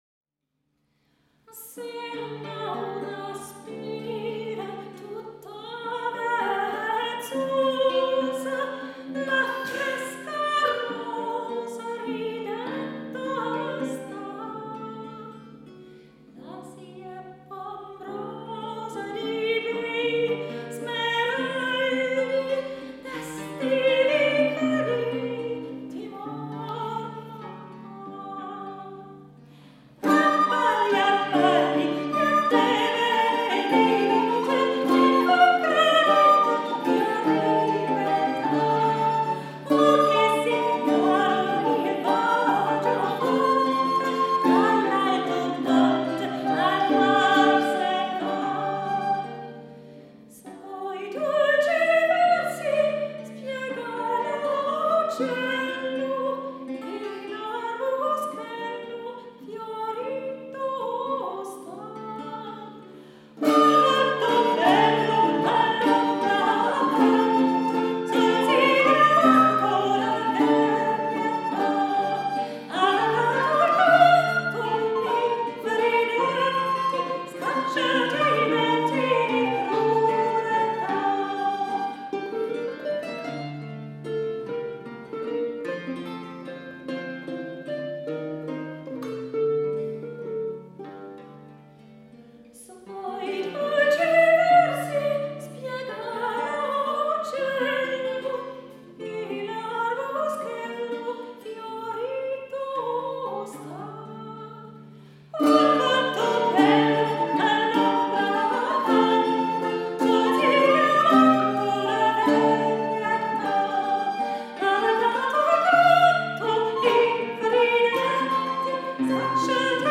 Die Stücke spiegeln höfische Liedkultur des 16. und 17. Jahrhunderts: